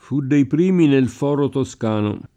foro [f0ro] s. m. («piazza; mercato; tribunale») — es. con acc. scr.: fu dei primi nel fòro toscano [